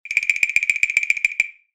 25 Rattles covering Shaker, Tambourine, and Various others
BW_x_JDR_DOP_Rattle_Processed_Random_02.mp3